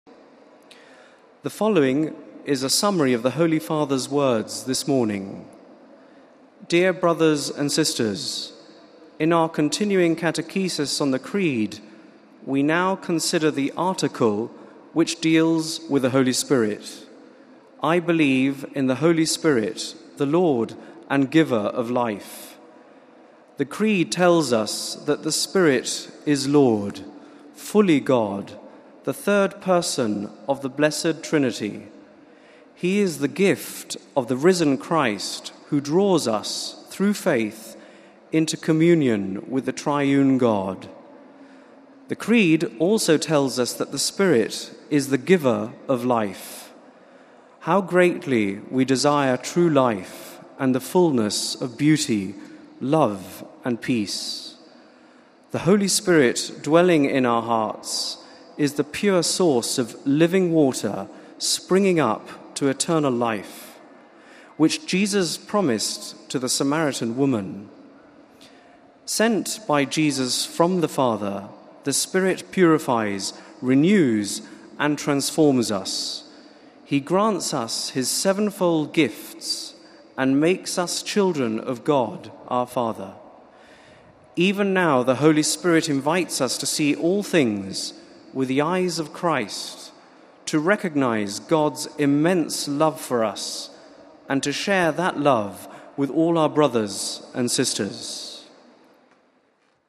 The general audience of May 8th was held in the open, in Rome’s St. Peter’s Square.
The aides then read summaries of the Pope’s catechesis in various languages, including in English.